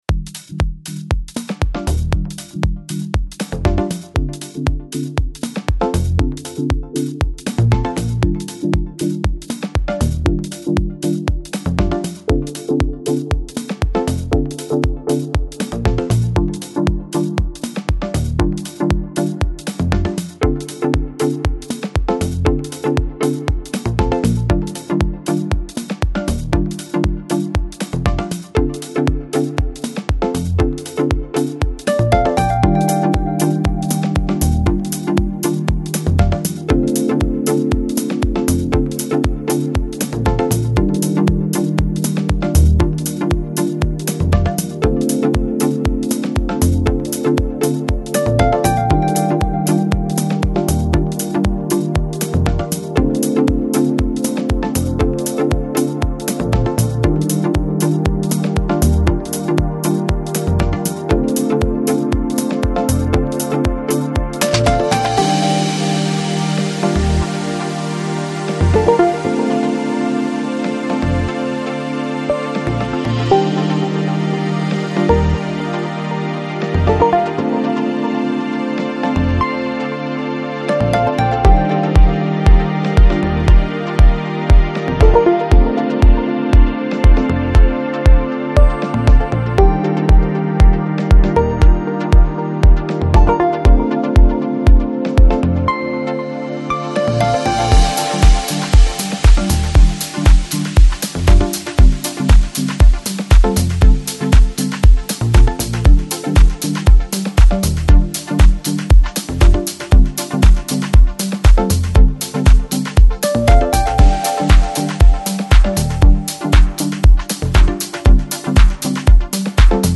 Жанр: Lounge, Chill Out, Electronic, Chill House